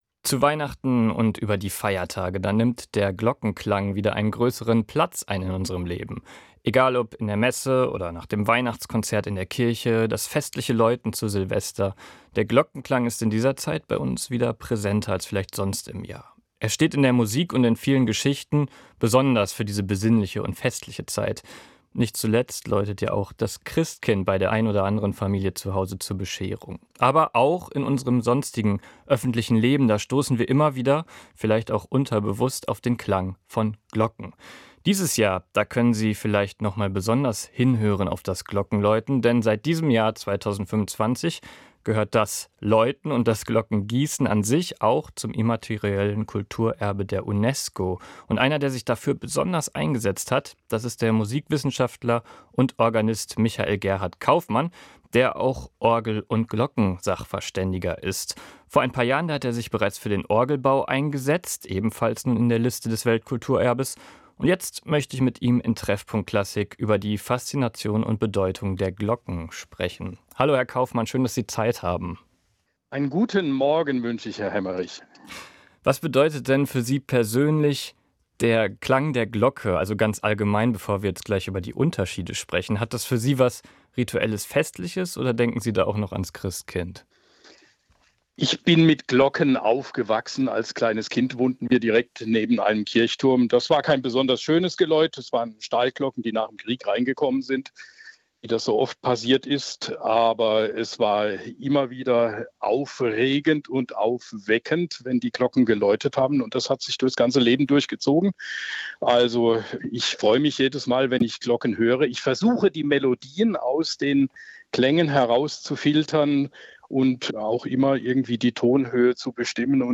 Musikgespräch
Das Interview führte